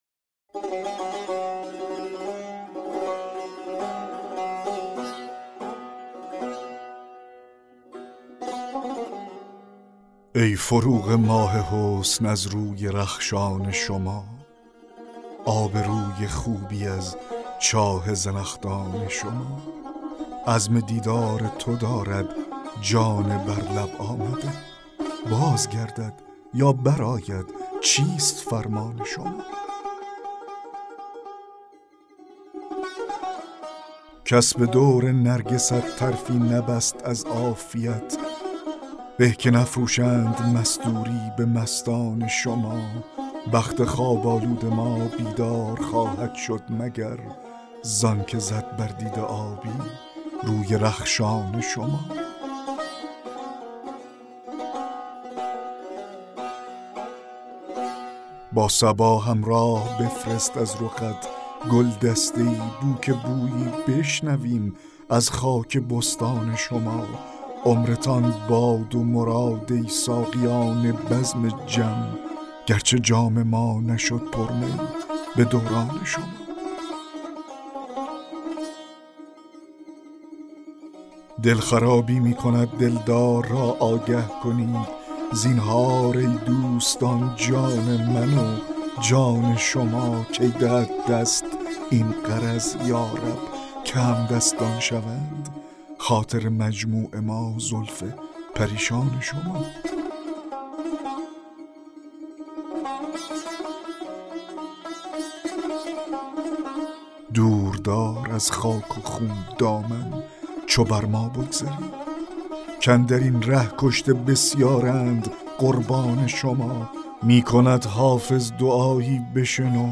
دکلمه غزل 12 حافظ